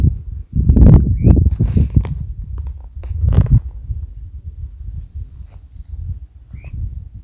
Yetapá Grande (Gubernetes yetapa)
Nombre en inglés: Streamer-tailed Tyrant
Localización detallada: Reserva Natural Silvestre Parque Federal Campo San Juan
Condición: Silvestre
Certeza: Fotografiada, Vocalización Grabada
Yetapa-grande_1.mp3